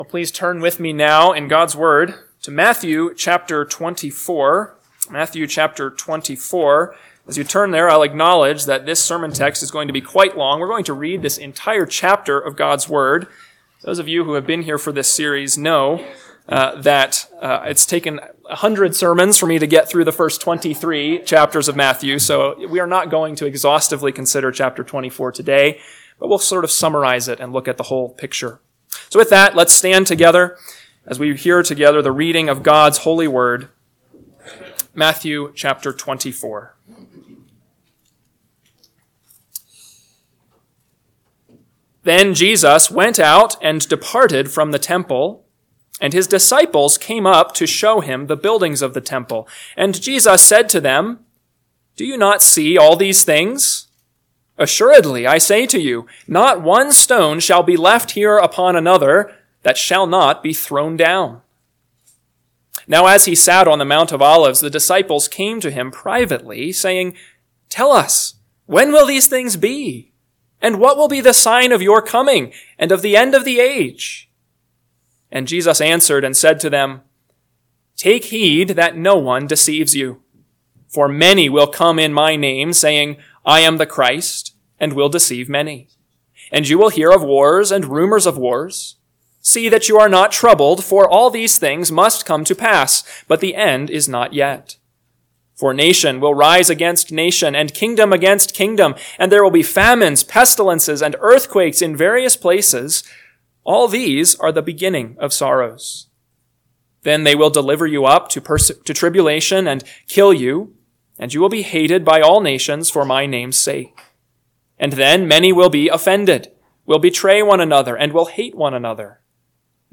AM Sermon – 12/1/2024 – Matthew 24:1-51 – Northwoods Sermons